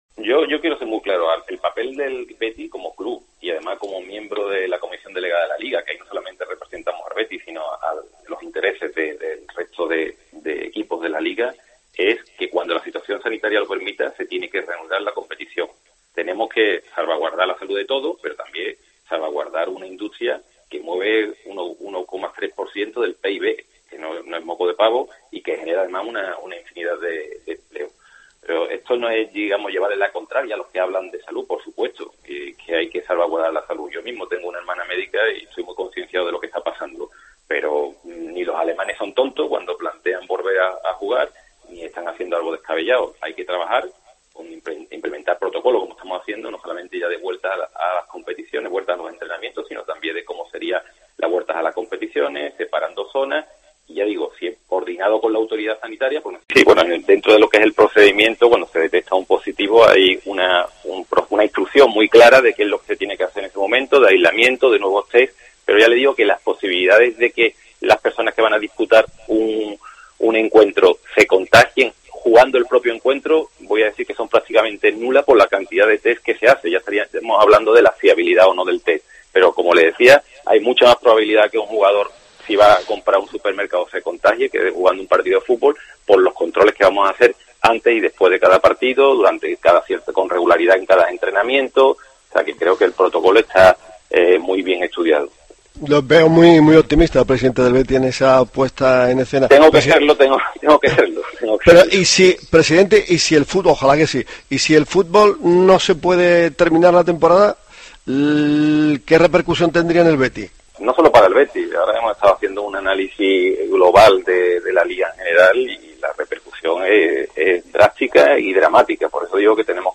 ÁNGEL HARO, EN LA ENTREVISTA CONCEDIDA ESTE LUNES A DEPORTES COPE SEVILLA